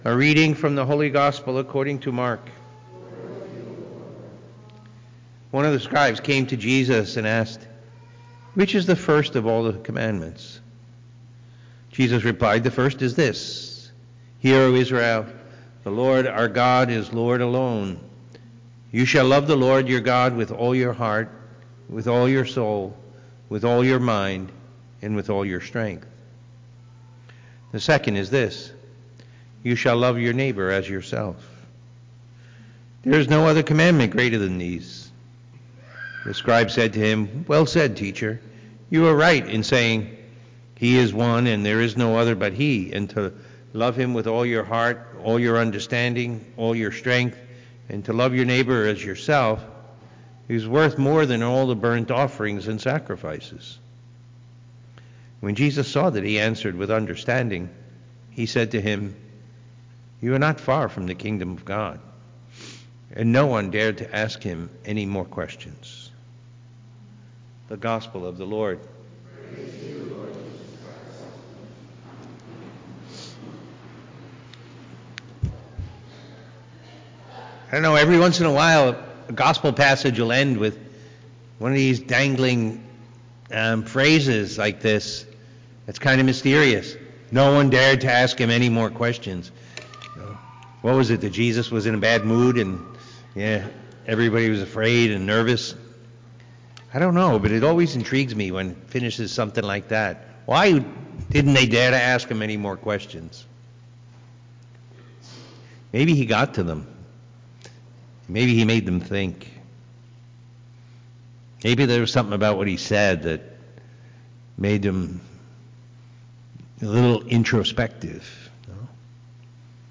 homily from the Sunday Mass